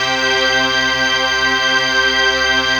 DM PAD2-51.wav